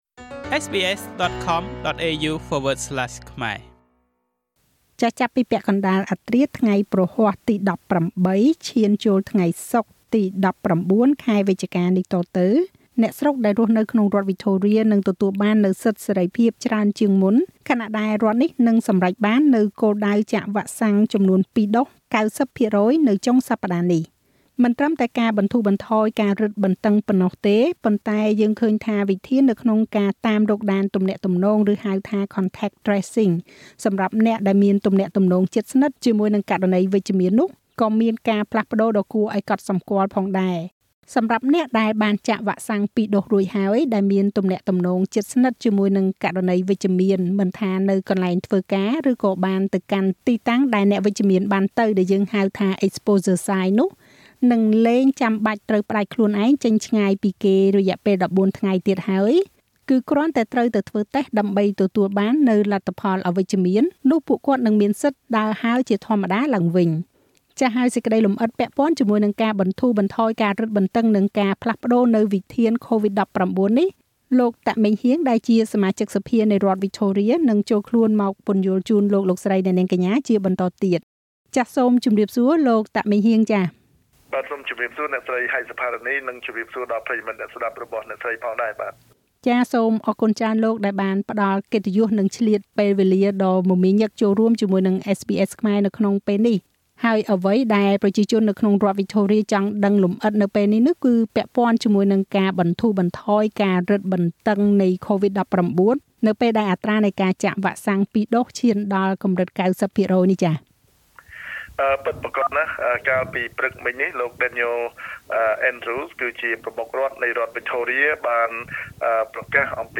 សូមស្តាប់នូវបទសម្ភាសន៍រវាងSBSខ្មែរជាមួយនឹង លោក តាក ម៉េងហ៊ាង សមាជិកសភានៃរដ្ឋវិចថូរៀដូចតទៅ។